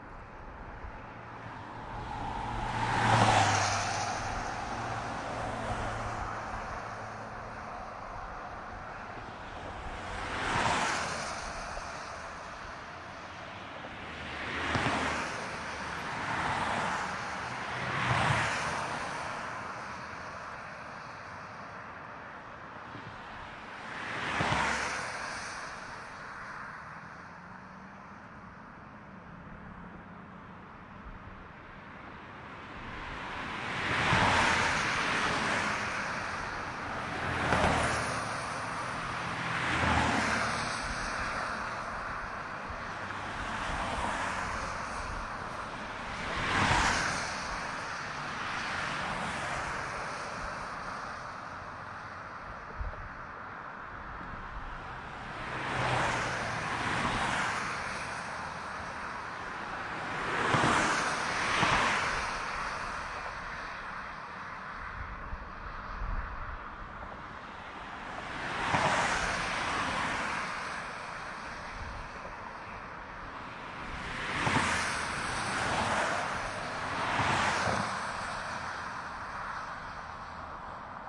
一个电影人的谵妄 " 交通繁忙的公路
描述：高速公路交通繁忙，有许多卡车。